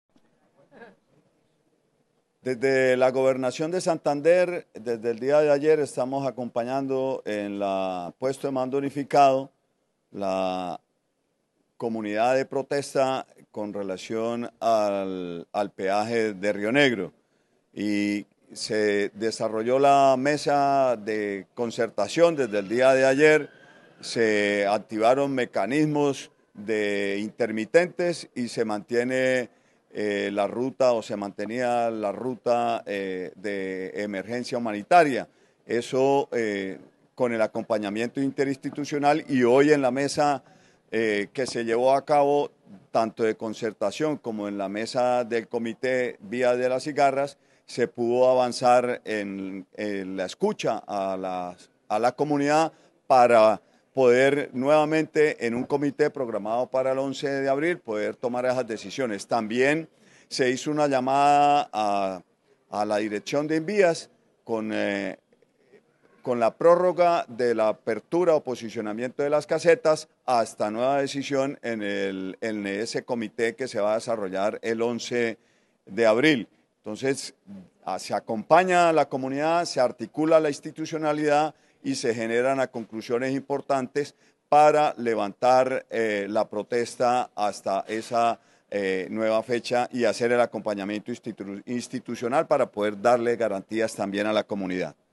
Oscar Hernández, Secretario del Interior de Santander